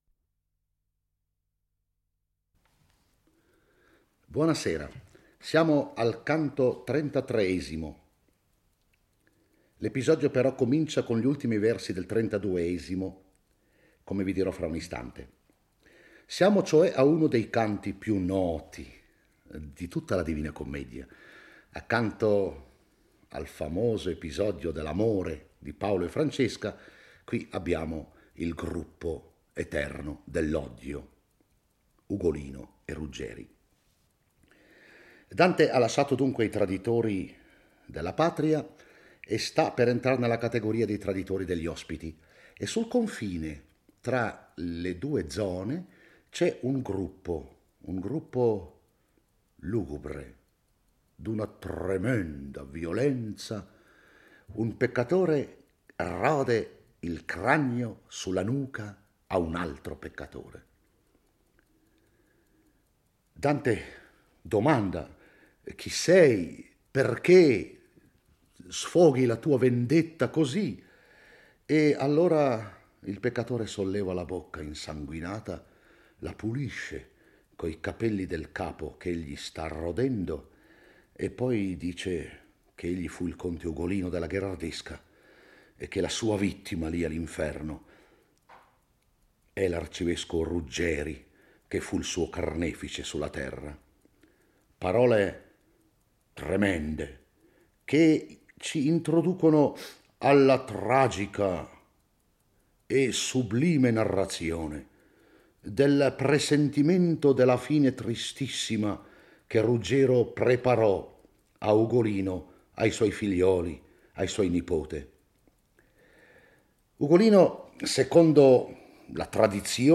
legge e commenta il XXXIII canto dell'Inferno